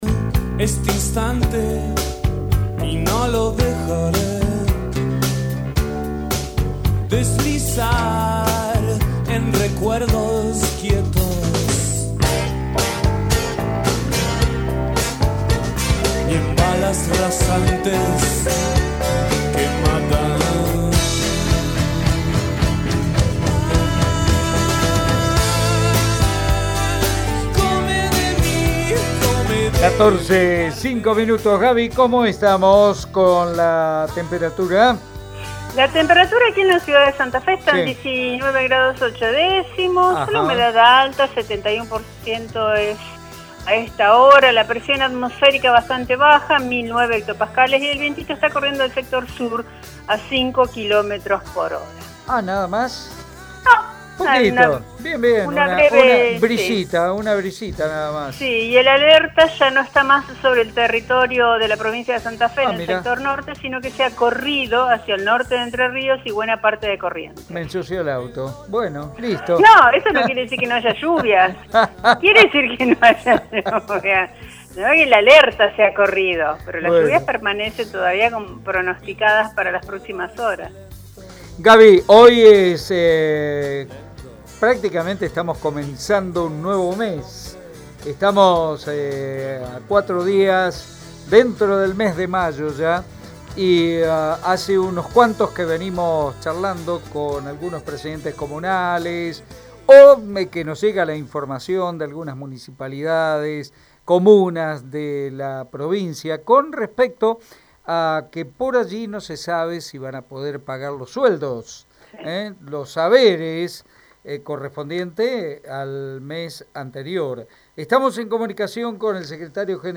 En dialogo con Radio EME